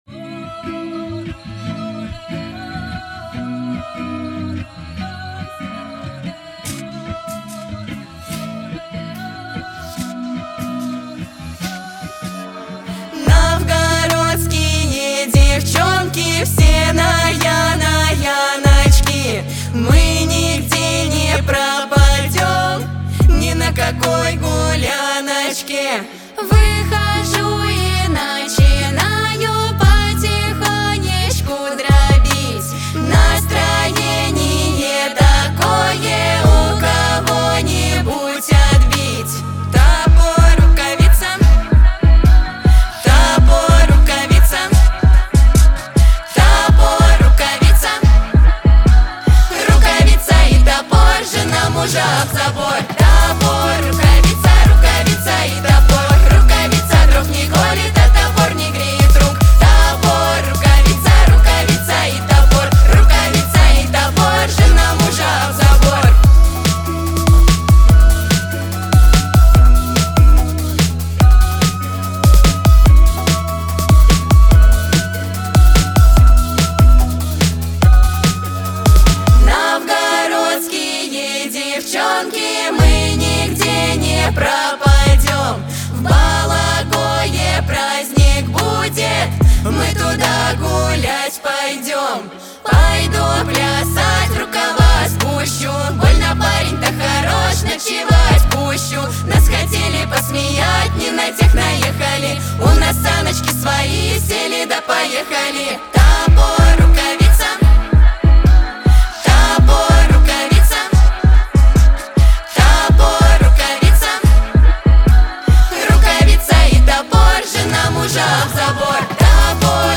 Шуточный трек с характерным русским народным стилем.